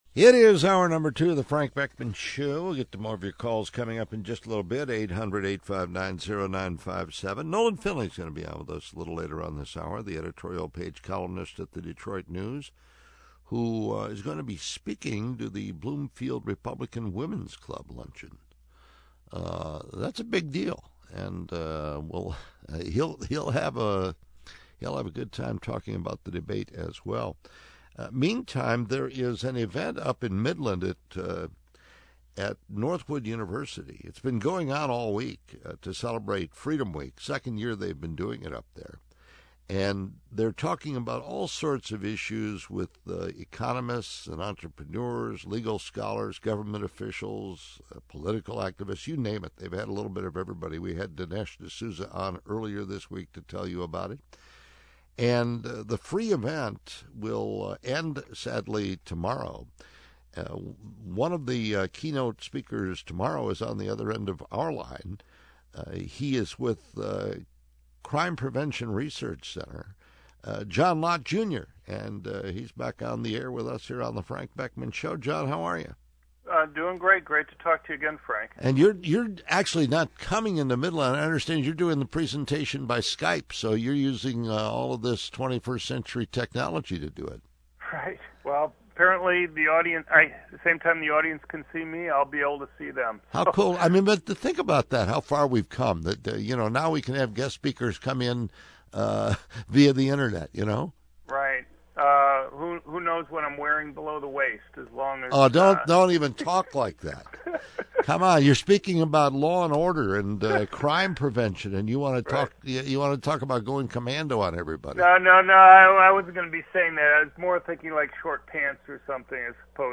CPRC on WJR to talk about the economics of crime, giving a preview of Lott’s talk at Northwood University